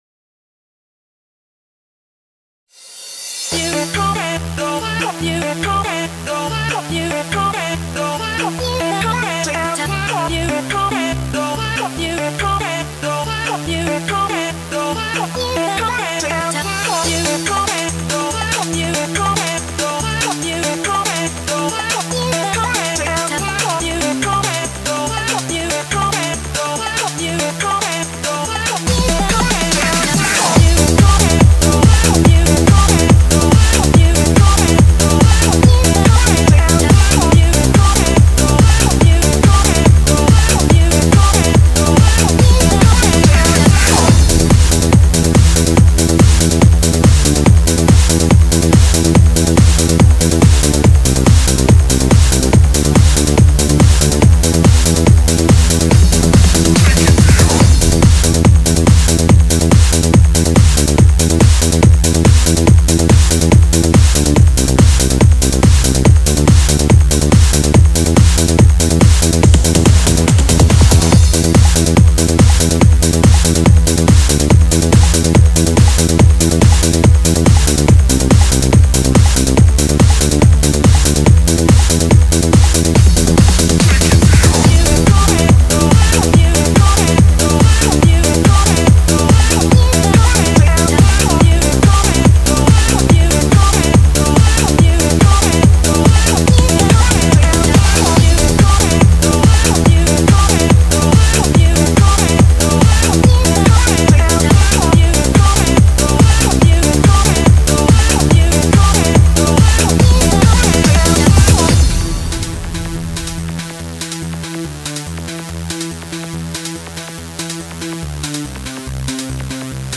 Eurodance